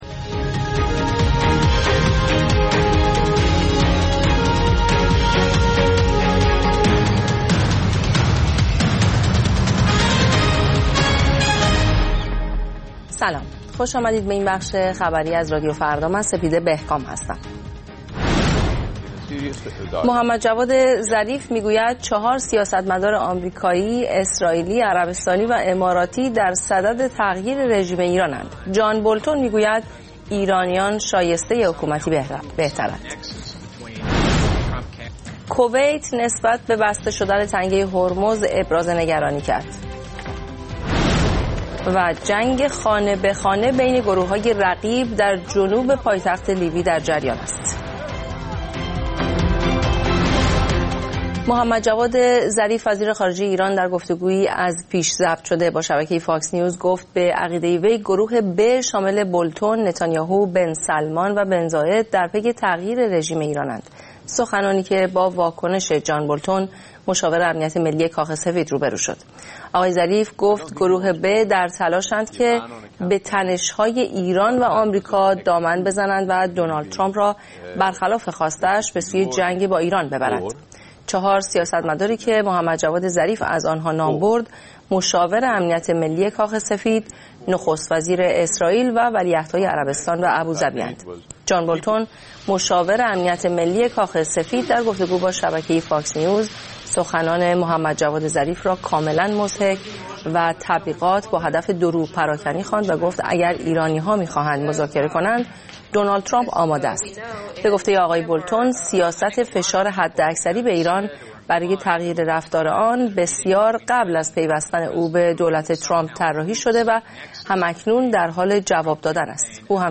اخبار رادیو فردا، ساعت ۹:۰۰